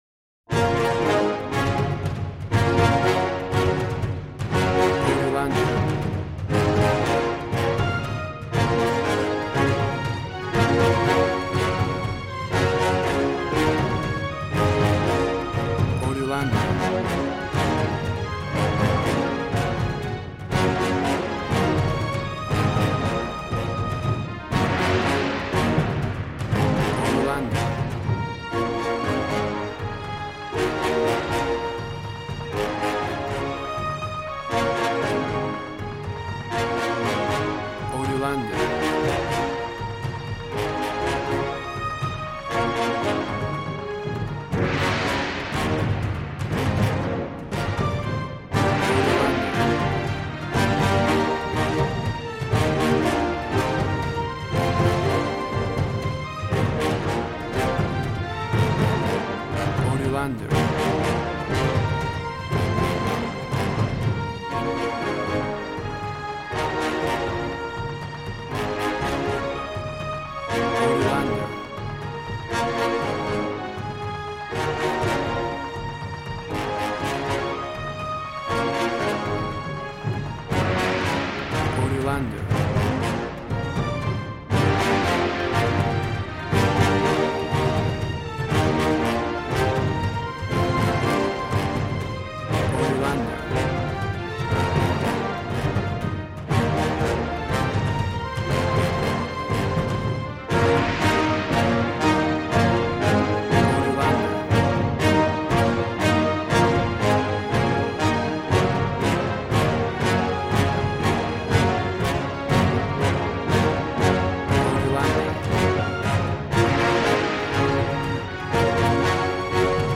Action and Fantasy music for an epic dramatic world!
Tempo (BPM): 120